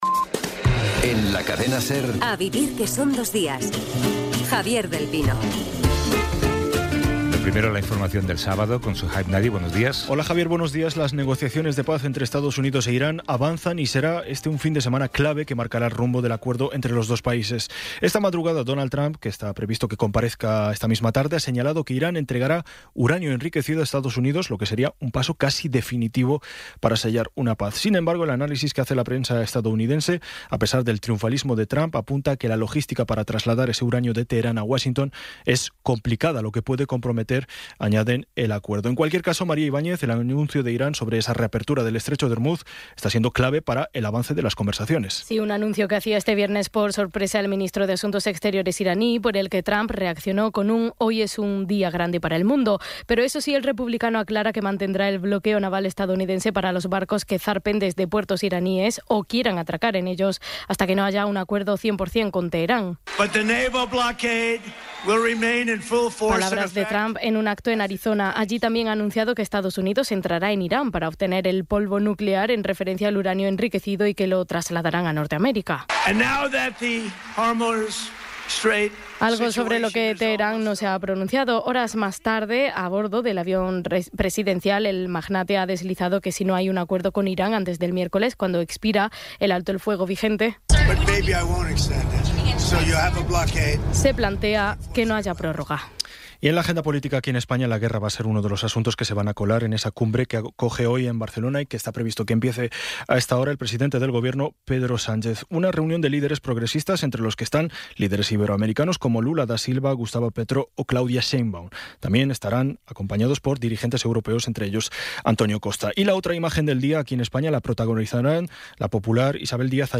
Resumen informativo con las noticias más destacadas del 18 de abril de 2026 a las nueve de la mañana.